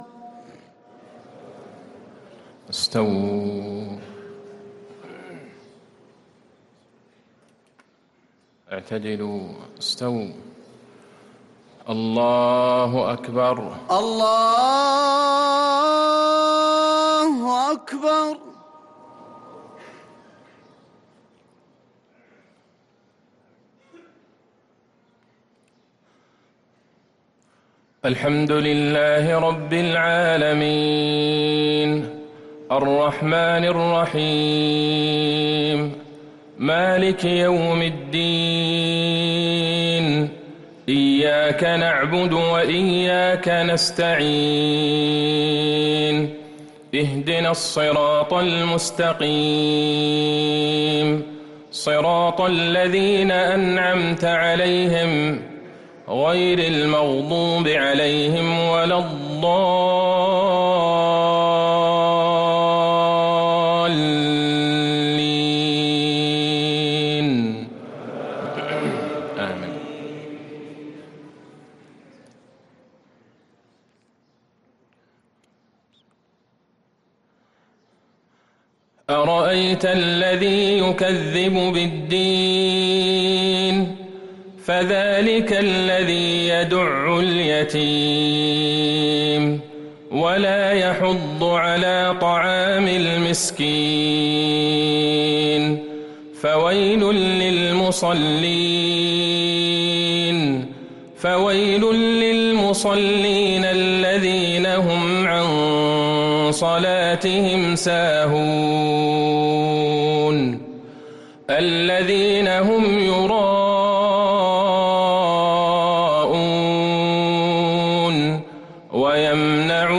مغرب الخميس ٥ صفر ١٤٤٤هـ | سورتي الماعون و الفلق | Maghrib prayer from Sarah Al-Maa'un and Al-Falaq 1-9-2022 > 1444 🕌 > الفروض - تلاوات الحرمين